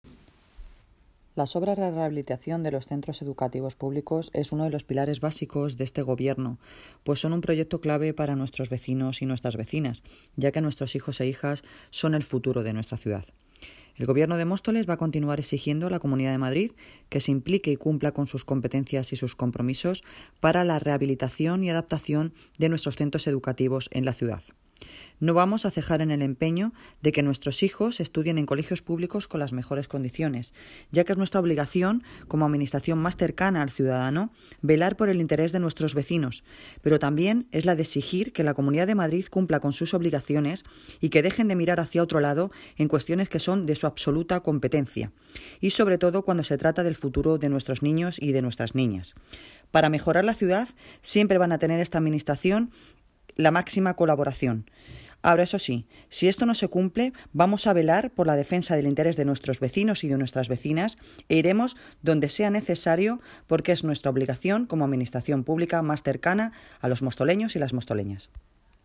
Audio - Noelia Posse (Alcaldesa de Móstoles) Sobre reformas de colegios